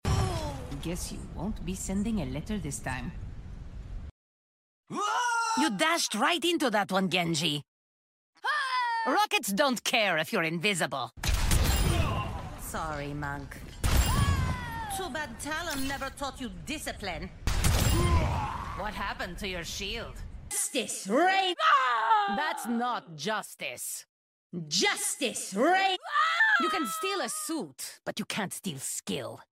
Pharah Elimination Voice Lines…